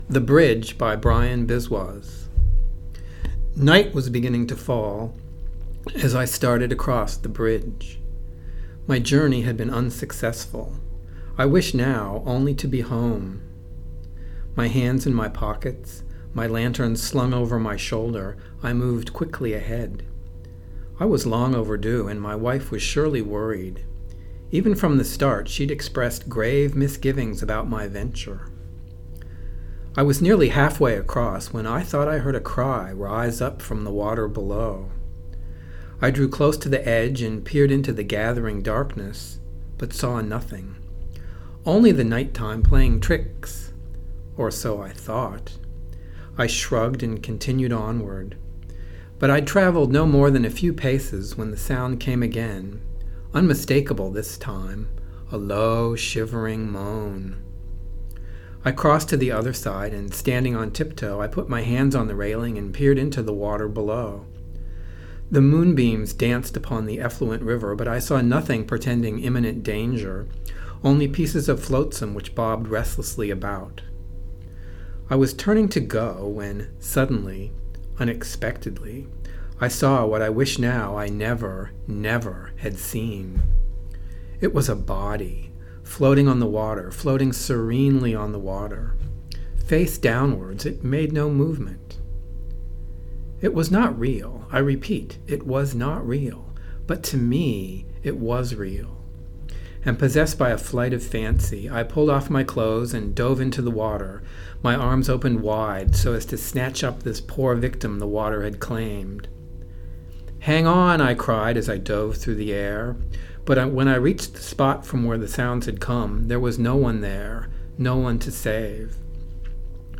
"The Bridge," read November 2020 at a North Carolina Writers Network talk on Magical Realism.